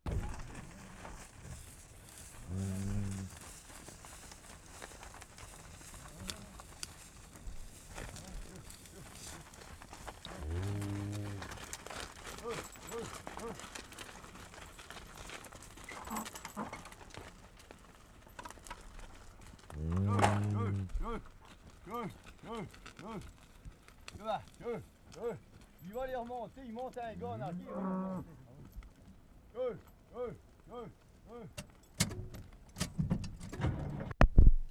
WORLD SOUNDSCAPE PROJECT TAPE LIBRARY
ST. LAURENT - NORTH SHORE, QUEBEC Oct. 26, 1973
ST. CATHERINE, HERDING COWS ON DIRT ROAD 0'35"